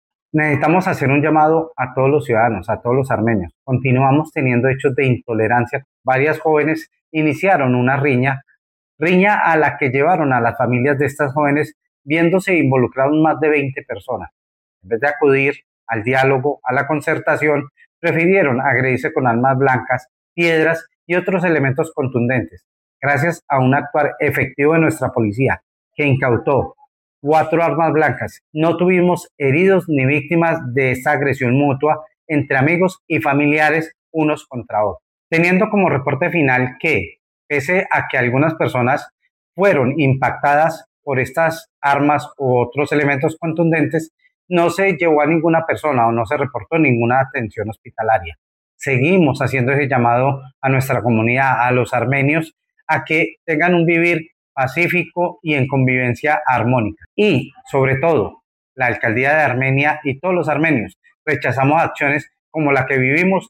Secretario de Gobierno de Armenia